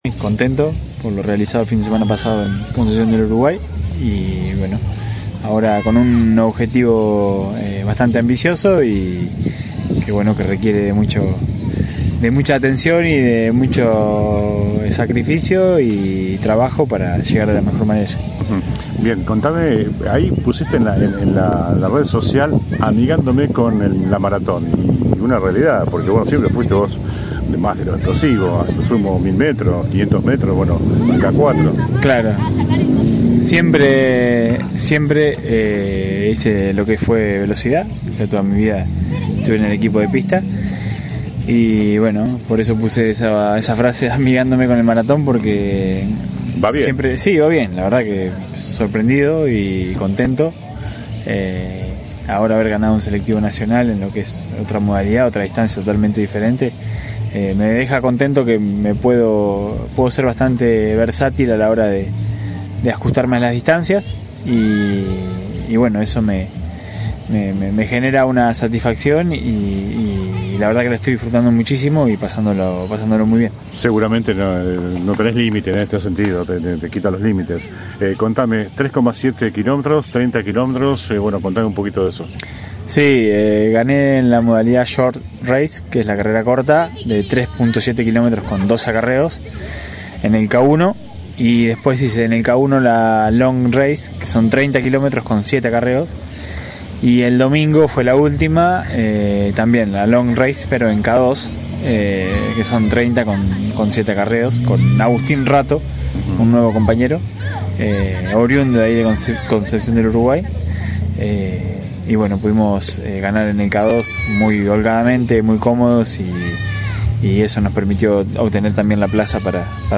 Conversamos con el olímpico el sábado pasado en el Parque Plaza Montero de Las Flores. Sentados a la vera de la laguna del Difunto Manuel, juani reflejó lo vivido en Entre Ríos la semana pasada.
AUDIO DE LA ENTREVISTA COMPLETA A JUANI CACERES